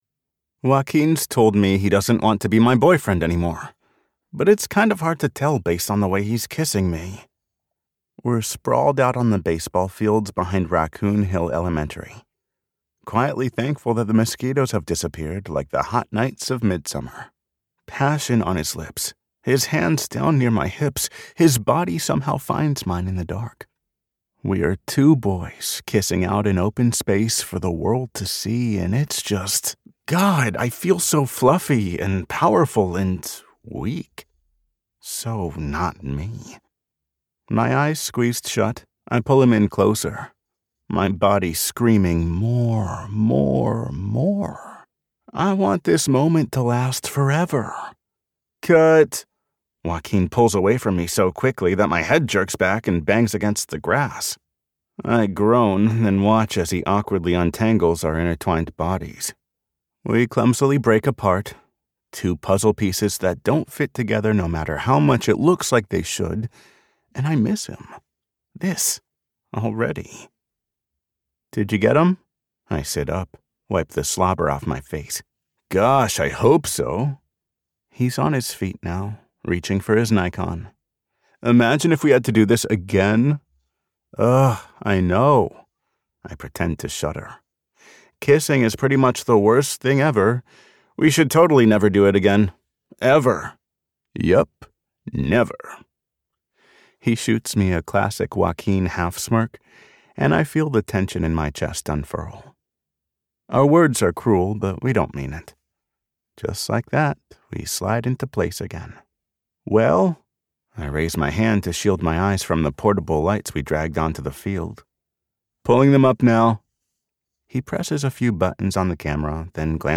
Related Audiobooks